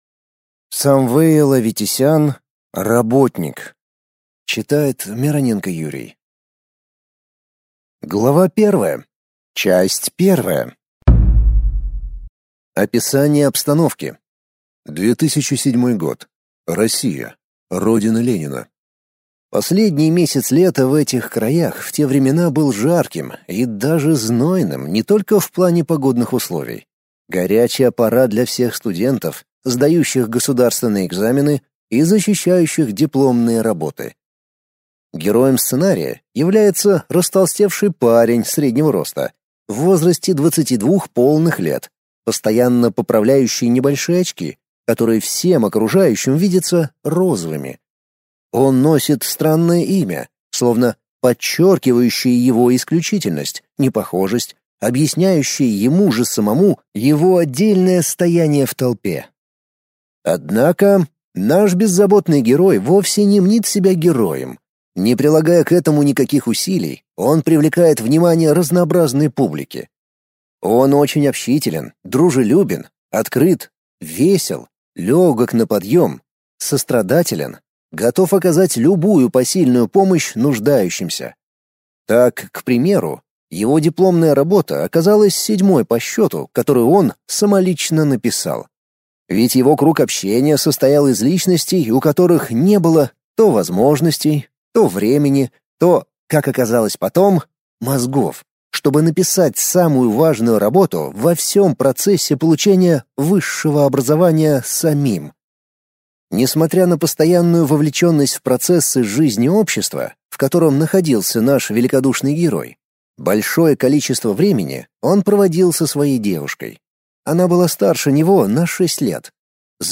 Аудиокнига Работник | Библиотека аудиокниг